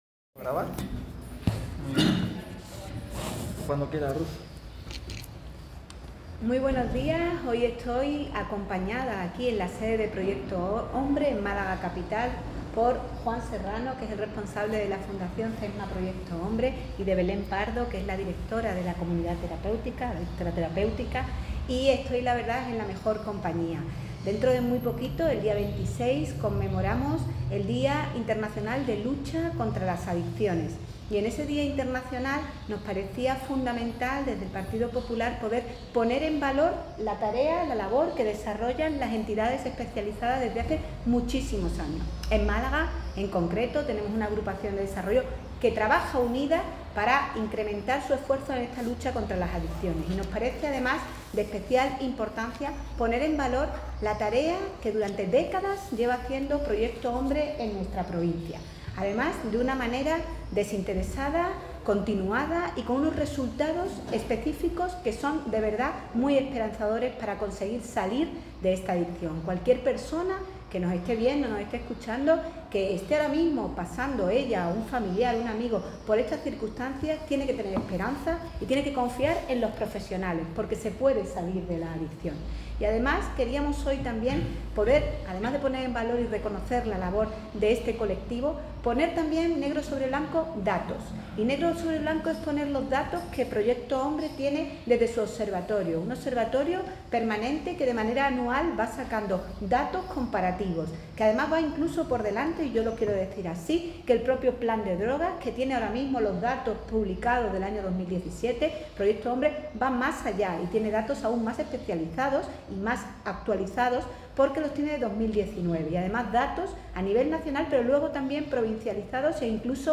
Así lo ha manifestado durante una visita a la sede de Proyecto Hombre en Málaga con motivo de la próxima conmemoración, el 26 de junio, del Día Internacional de la Lucha contra las Adicciones.